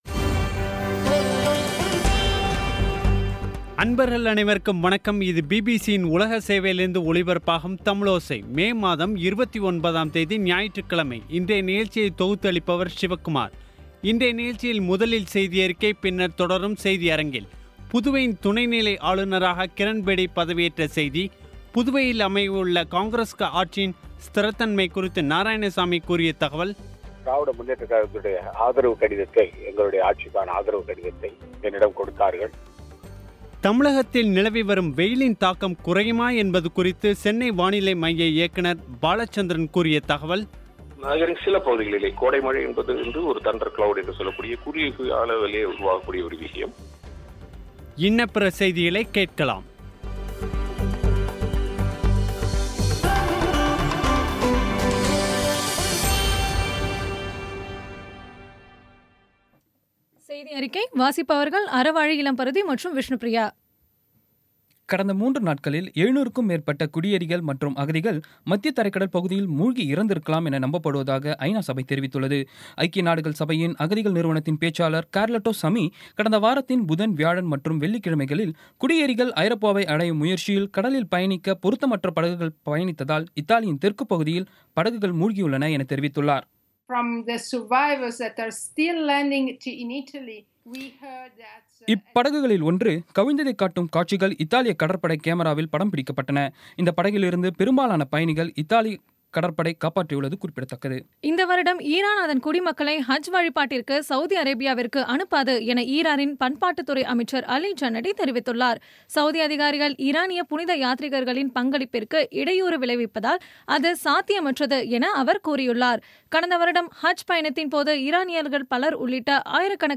இன்றைய நிகழ்ச்சியில் முதலில் செய்தியறிக்கை , பின்னர் செய்தியரங்கில்